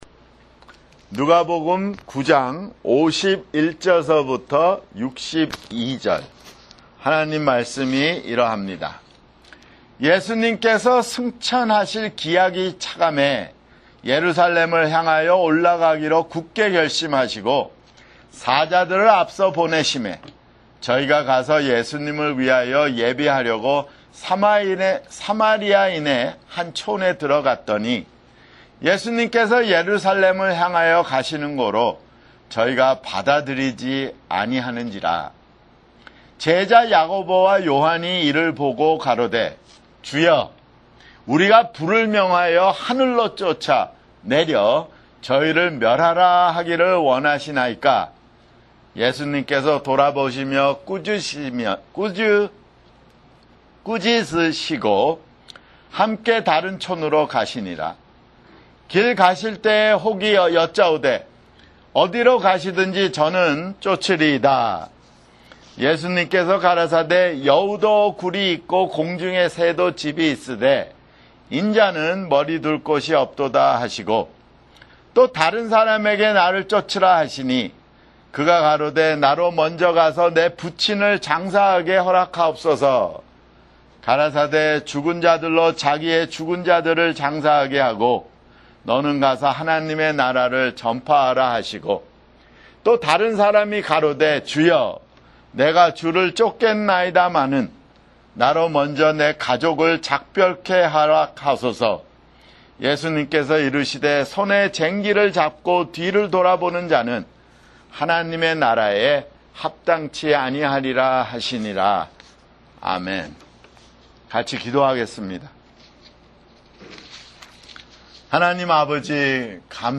[주일설교] 누가복음 (68)